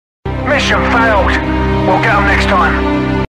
Mission Failed we'll get em next time Sound Effect.mp3